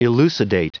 Prononciation du mot elucidate en anglais (fichier audio)
Prononciation du mot : elucidate